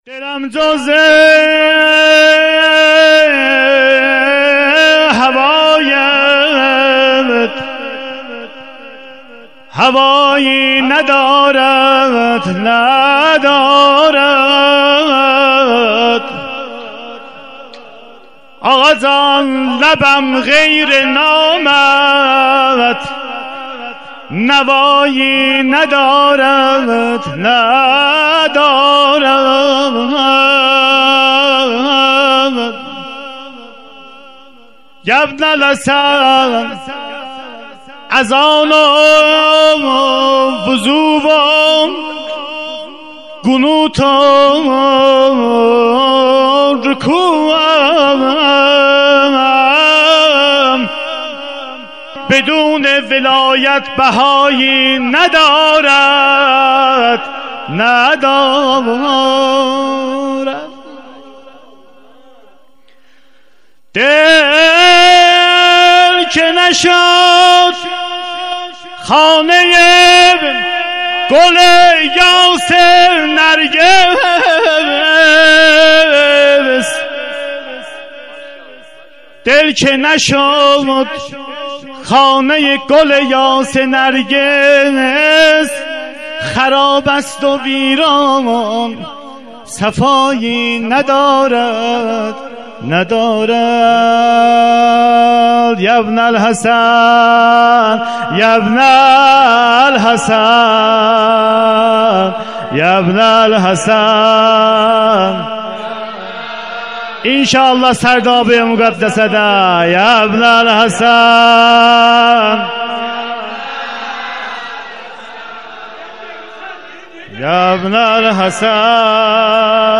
مدح
مراسم نیمه شعبان سال 97